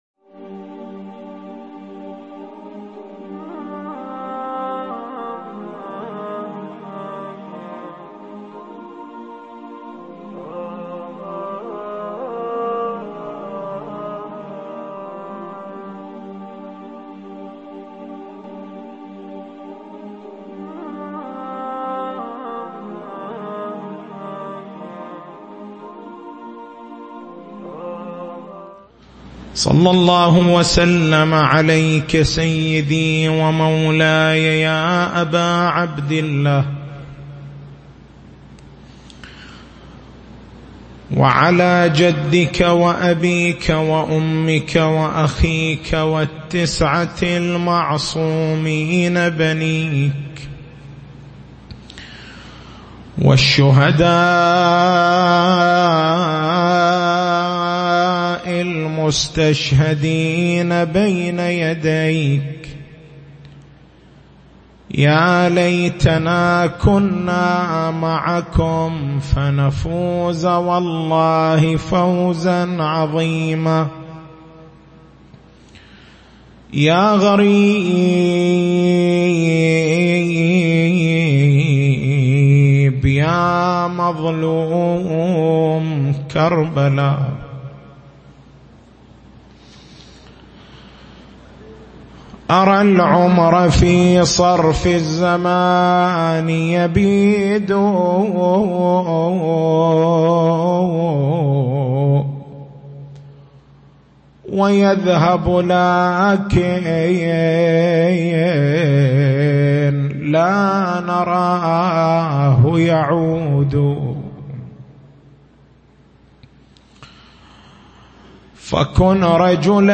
تاريخ المحاضرة: 06/09/1439 نقاط البحث: الفرق بين الأسماء والصفات الإلهية منظار السنخ الوجودي منظار الوجود اللفظي أقسام الأسماء الحسنى أسماء الذات أسماء الصفات أسماء الأفعال لماذا سُمِّيَت أسماء الله بالحسنى؟
تسجيل حسينية الحمران